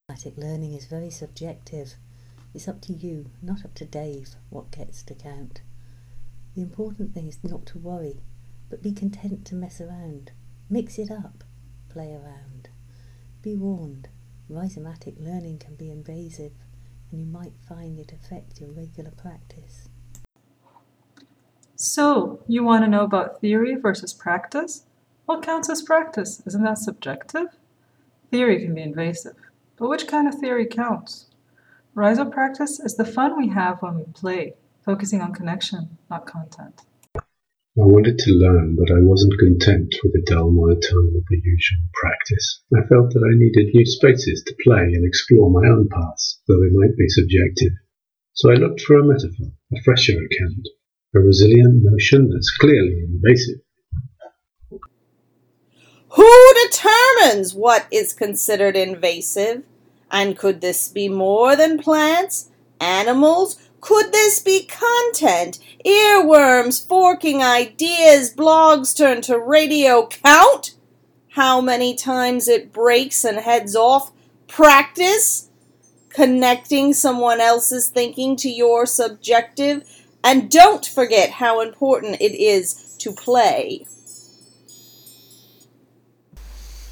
The artefacts produced included a collective poem in the form of a sestina (here). The group also recorded themselves reading their verses - -